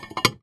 R - Foley 29.wav